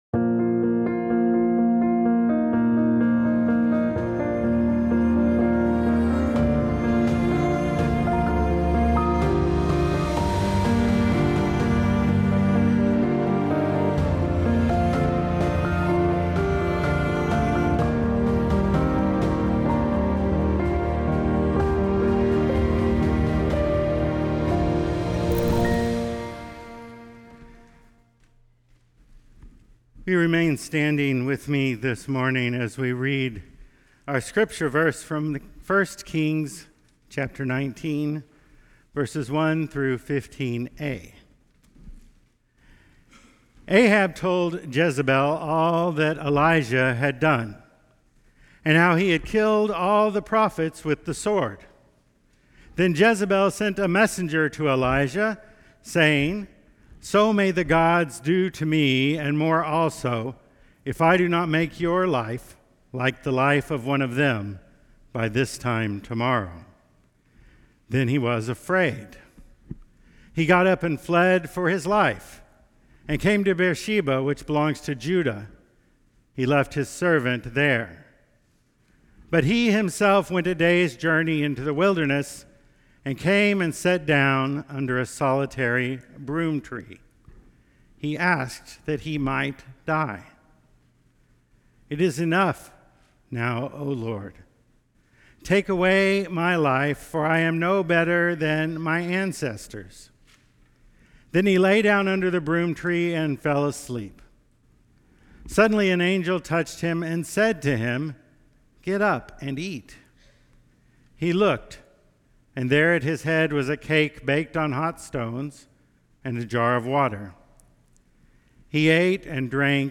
1 Kings:1-15a Service Type: Traditional https